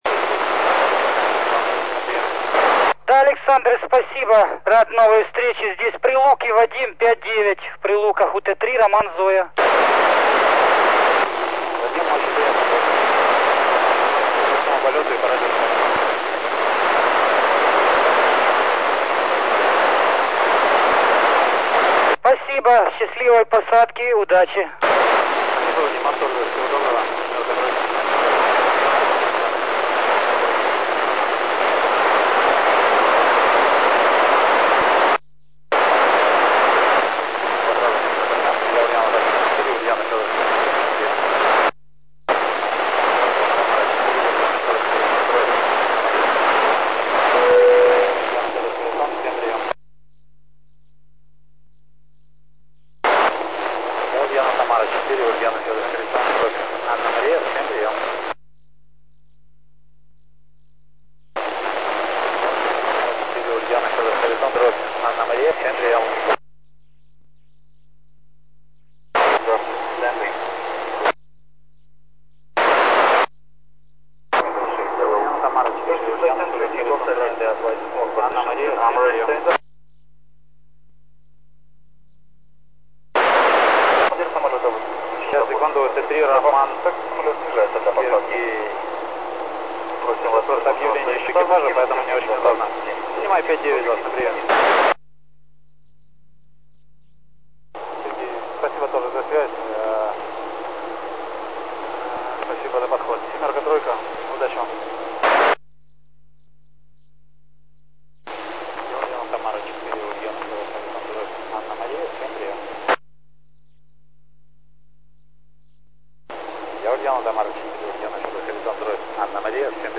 Начало » Записи » Записи радиопереговоров - любители и пираты
на 145.500, с борта самолета (рейс Москва-Киев)
общий вызов